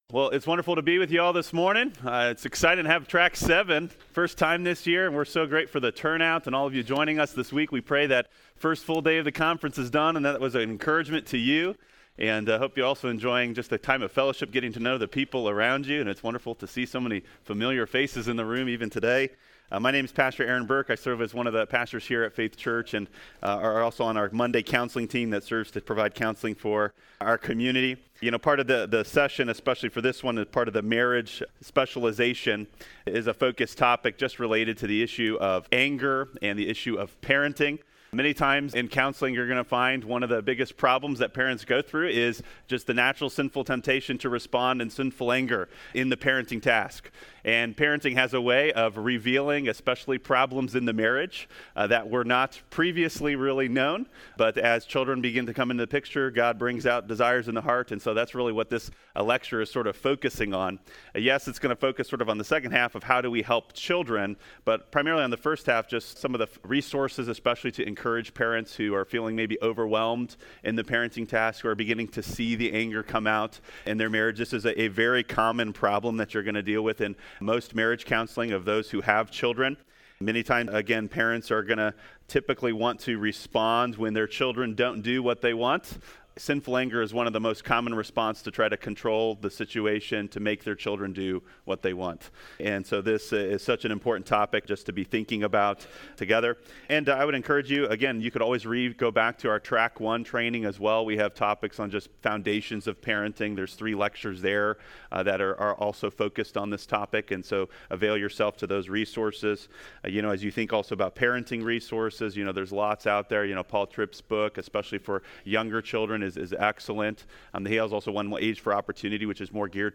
This is a session from the Biblical Counseling Training Conference hosted by Faith Church in Lafayette, Indiana.
You may listen to the first 10 minutes of this session by clicking on the "Preview Excerpt" button above.